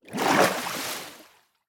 Minecraft Version Minecraft Version latest Latest Release | Latest Snapshot latest / assets / minecraft / sounds / ambient / underwater / exit3.ogg Compare With Compare With Latest Release | Latest Snapshot